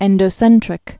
(ĕndō-sĕntrĭk)